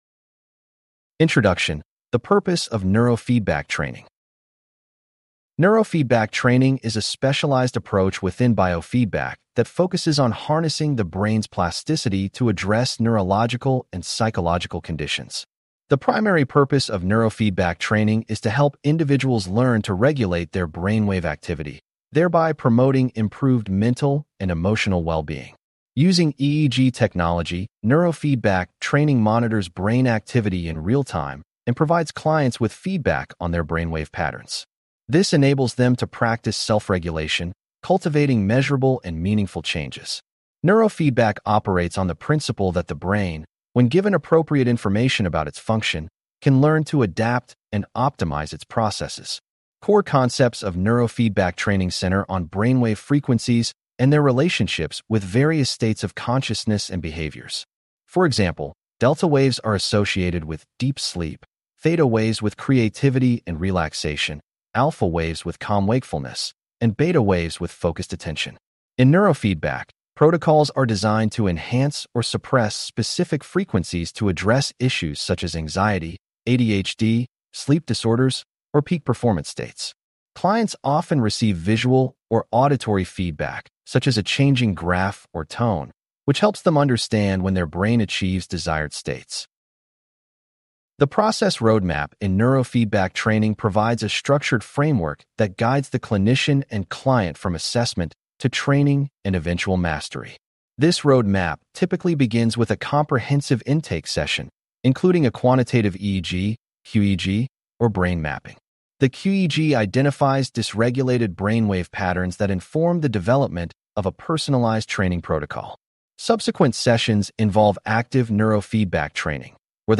This unit presents a brief demonstration of neurofeedback training and three case examples that illustrate the steps/decision tree for applying client assessment data to neurofeedback protocol selection and treatment/training planning. Please click on the podcast icon below to hear a full-length lecture.